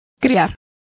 Complete with pronunciation of the translation of foster.